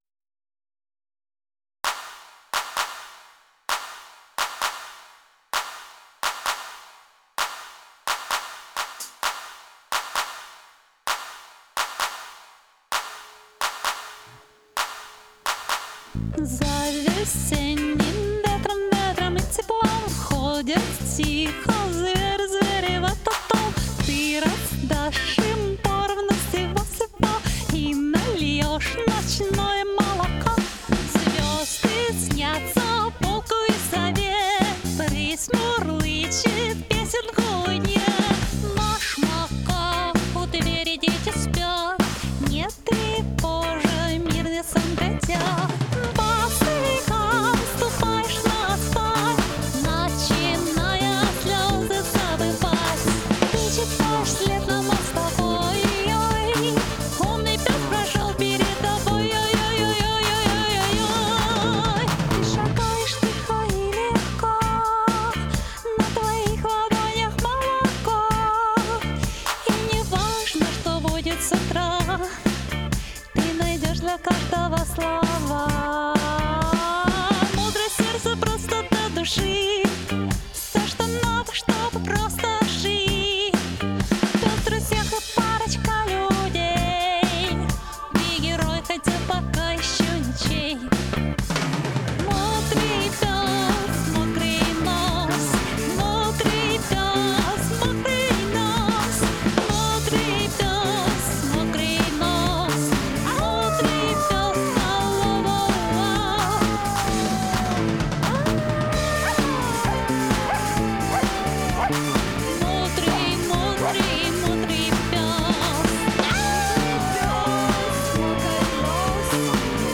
• Жанр: Фолк
вокал
гитара